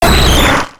Cri d'Amonistar dans Pokémon X et Y.